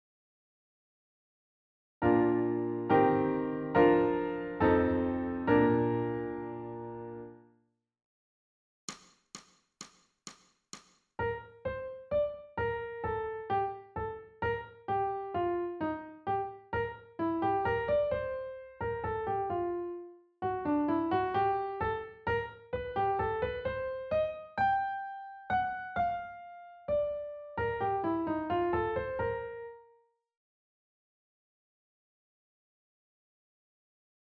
ソルフェージュ 聴音: 2-1-29